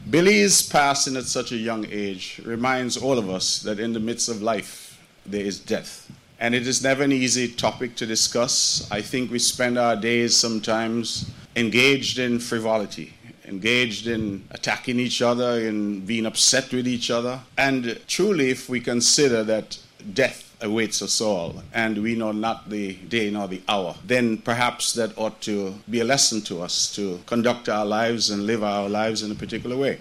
Premier of Nevis, Hon. Mark A.G. Brantley also spoke during the ceremony: